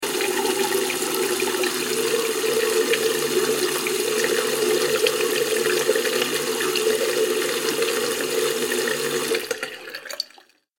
دانلود آهنگ آب 52 از افکت صوتی طبیعت و محیط
دانلود صدای آب 52 از ساعد نیوز با لینک مستقیم و کیفیت بالا
جلوه های صوتی